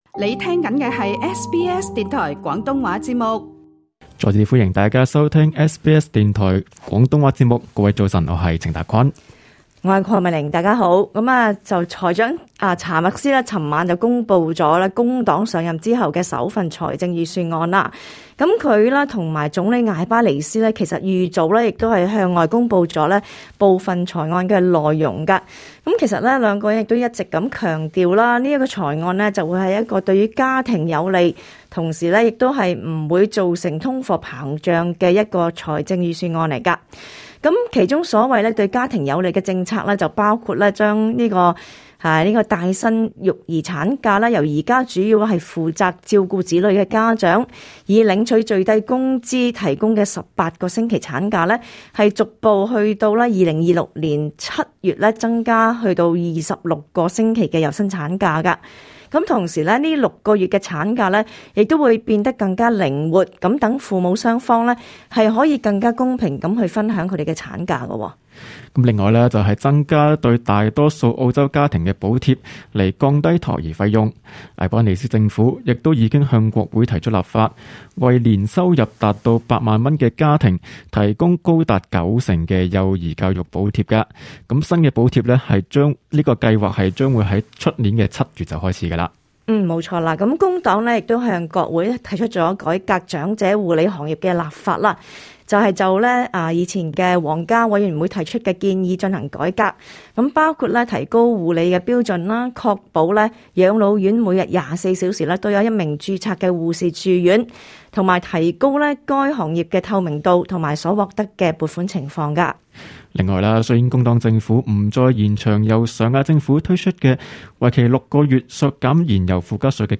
*本節目內嘉賓及聽眾意見並不代表本台立場 READ MORE 【財案2022】專家稱財案不完整：「下一代肯定是輸家」 兩黨聯盟今晚回應財案 料轟未應對能源價格飆升 財案預測：家庭能源費兩年加五成 瀏覽更多最新時事資訊，請登上 廣東話節目 Facebook 專頁 、 MeWe 專頁 、 Twitter 專頁 ，或訂閱 廣東話節目 Telegram 頻道 。